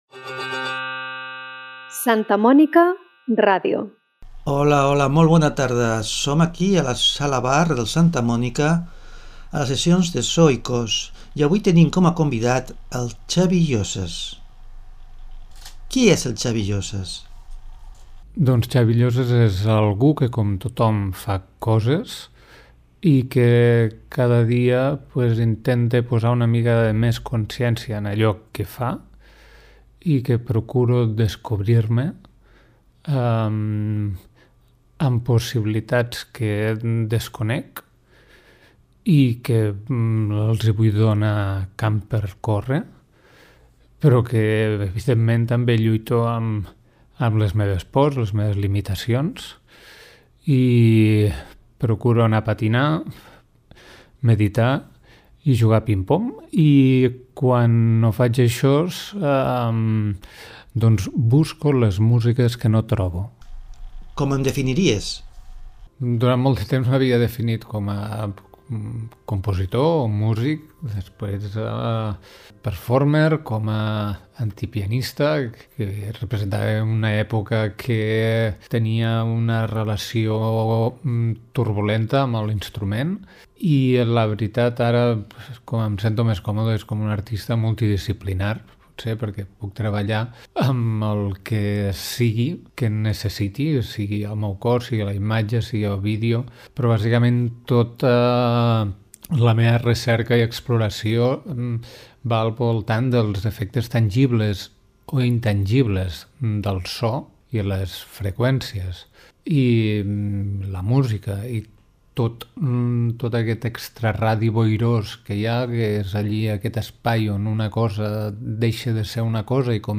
Concert en format de conferència sònica
Partint d’una sèrie de collages de locucions extretes de documentals, ràdios, telenotícies i pel·lícules, es construeix un fil argumental al voltant de les diferents repercussions tangibles i intangibles de la vibració sonora i la música.
Reflexions sobre què i com escoltem, i els possibles significats i valors que atorguem a allò que anomenem música. Un trajecte al·lucinant a través d’hipnòtics i curiosos paisatges sonors entrellaçats amb les últimes músiques destil·lades